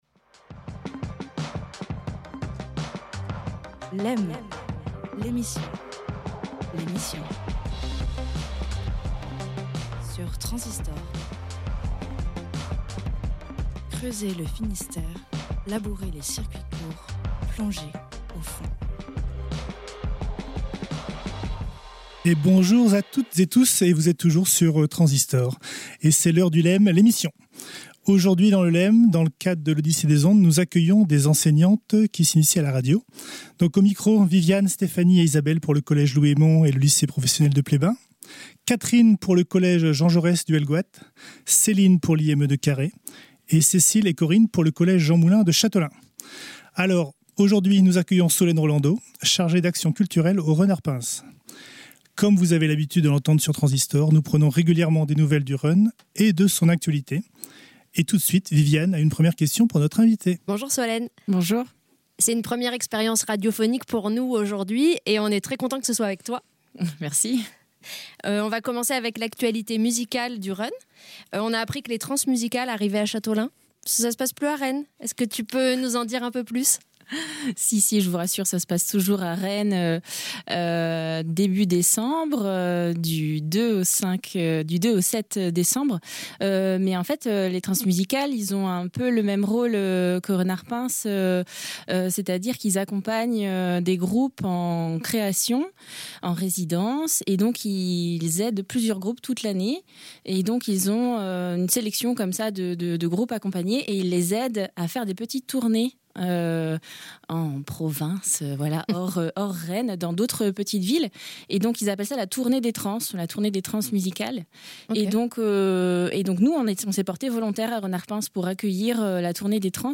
Une dizaine d’enseignants se sont glissés, le temps d’une matinée, dans la peau d’animateurs radio.
Accompagnés par l’équipe de la radio, les participants ont imaginé, préparé et enregistré une émission complète, consacrée à la vie culturelle du Run Ar Puñs, la salle de musiques actuelles de Châteaulin.